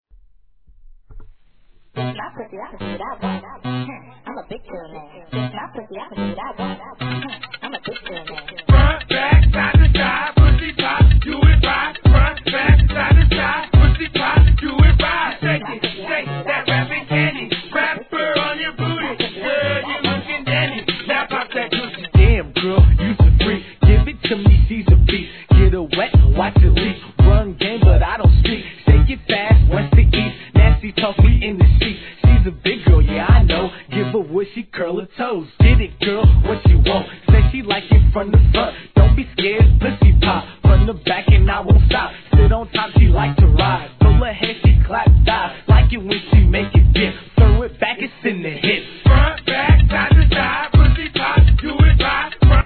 HIP HOP/R&B
コミカルなリズムにポーーーーーーーーーーーーーーーーーーーンが最高!!